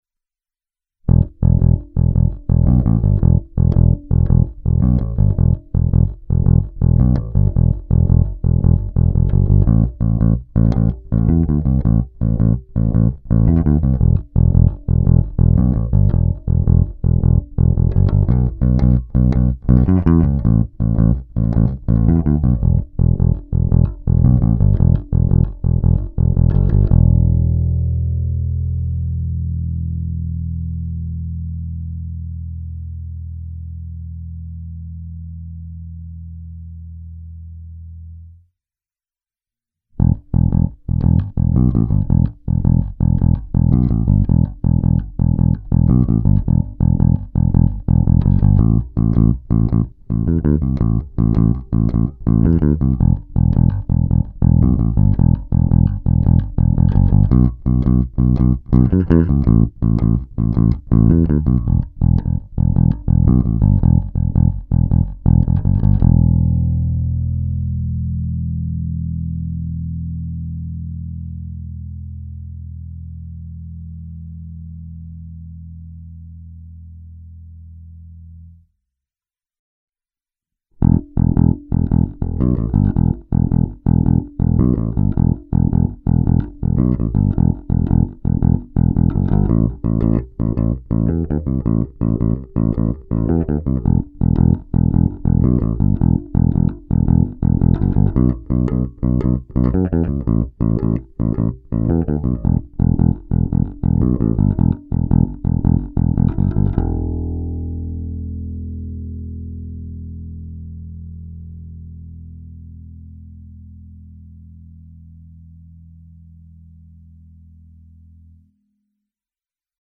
Nahrávky s korekcemi na středu a otevřenou tónovou clonou, hlazené struny Thomastik, pořadí: krkový snímač - oba snímače - kobylkový snímač.